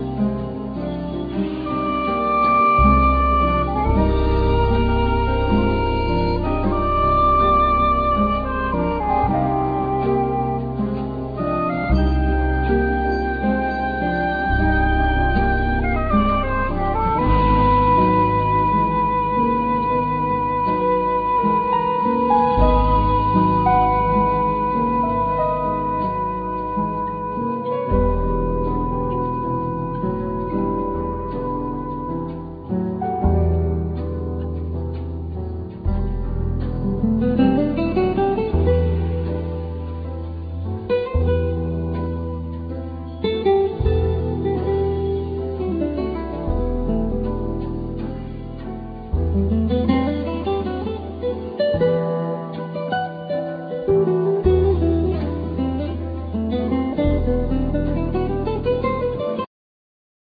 Classical&12 String Guitar,Synthsizer,Piano
Bass
Peucussions,Vocal
Drums,Hand Drums